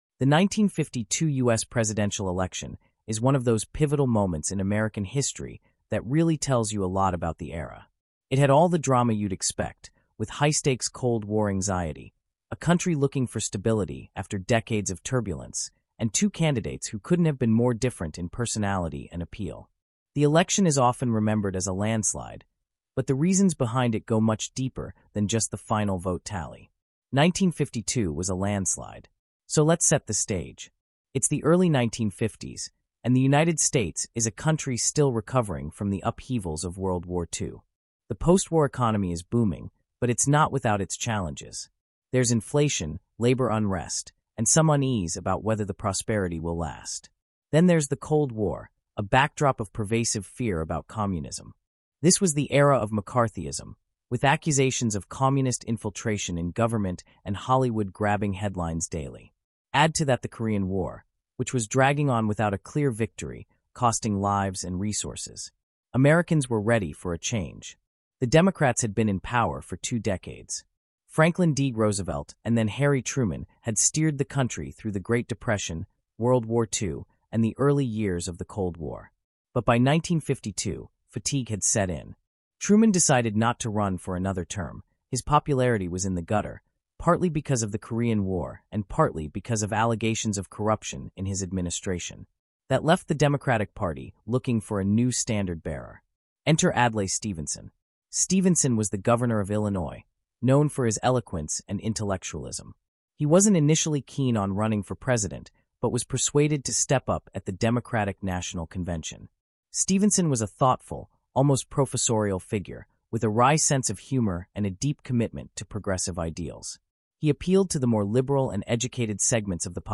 Disclosure: This podcast includes content generated using an AI voice model. While efforts were made to ensure accuracy and clarity, some voices may not represent real individuals.